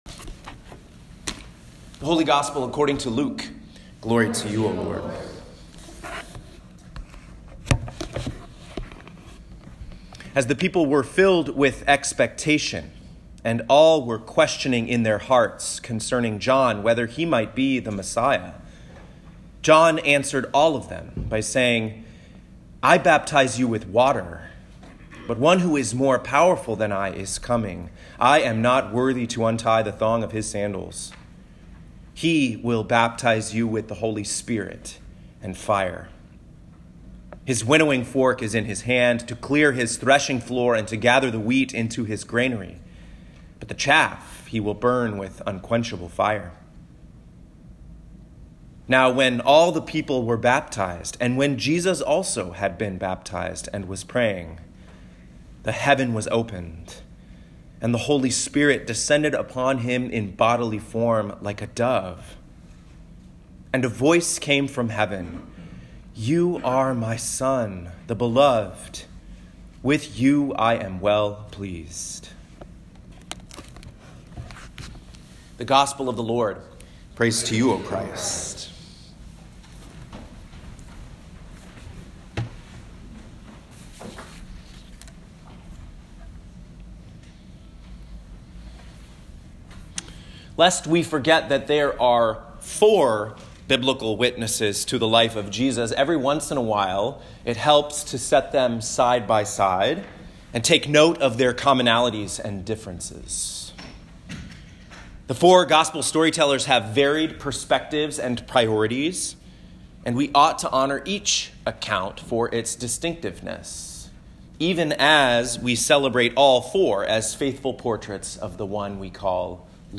Home › Sermons › Second Person Singular